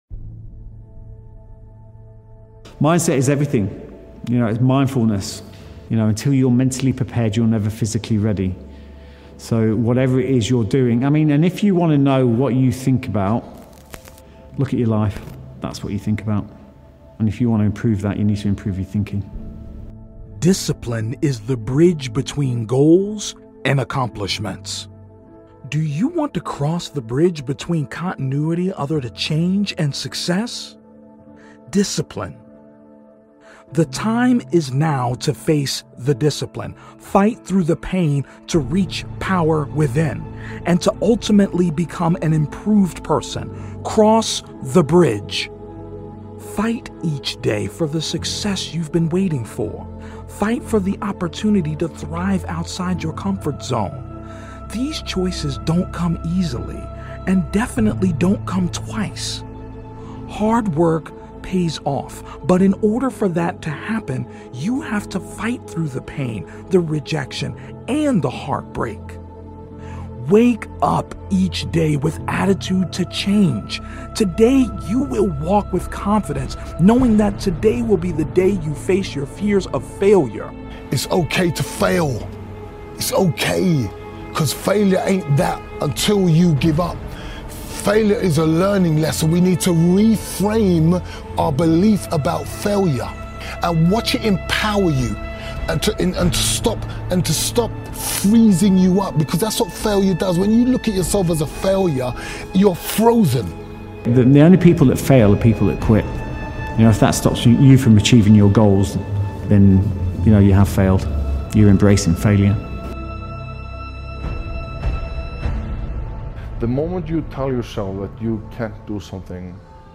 Reset Your Mindset: 30 Minutes of Speeches to Fuel Your Best Self